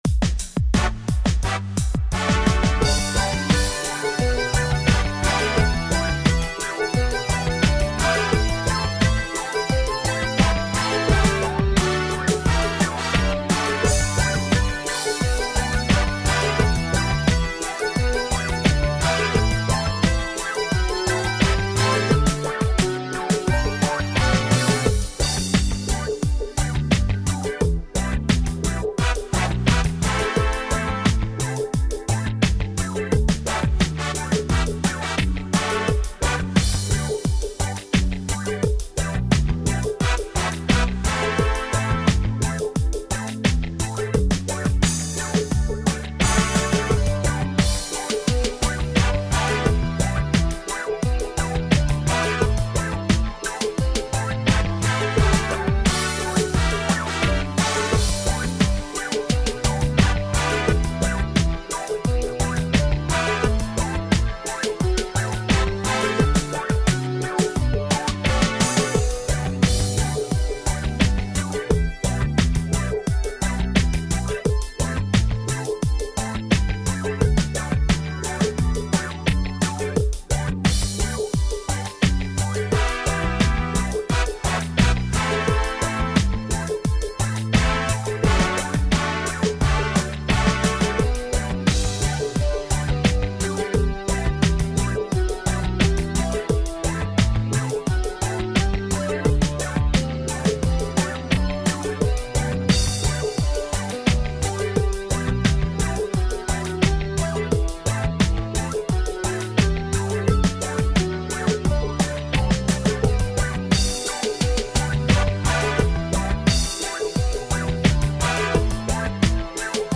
минусовка версия 49457